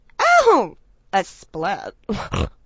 oh_a_split_snort.wav